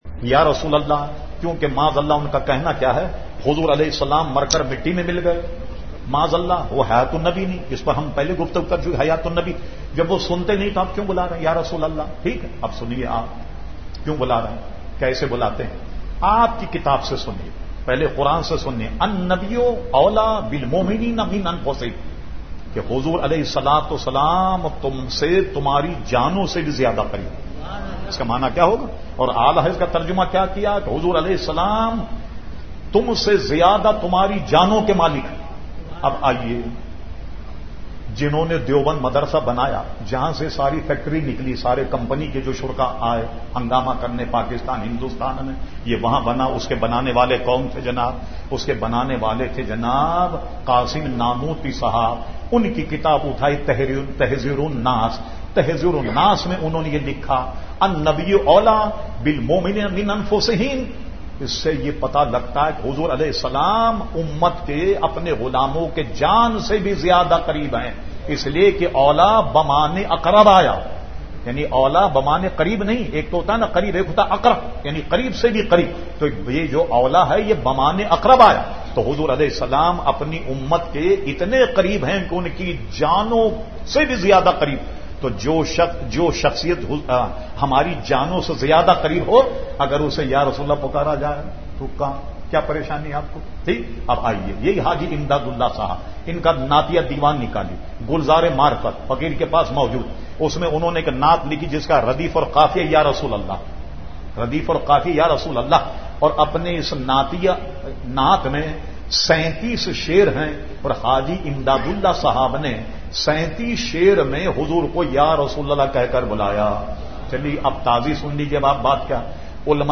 Q/A Program held on Tuesday 31 August 2010 at Jamia Masjid Ameer e Hamza Nazimabad Karachi.